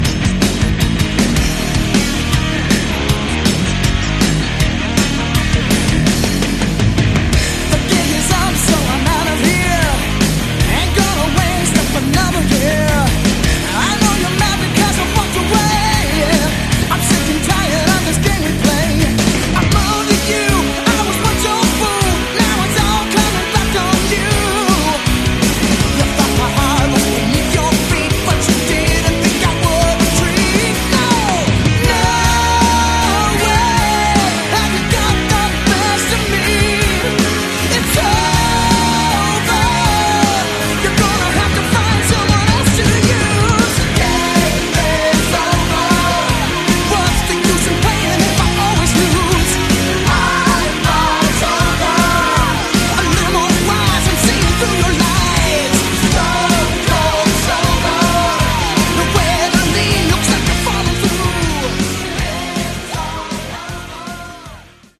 Category: Melodic Power Metal
vocals
guitars
keyboards
bass
drums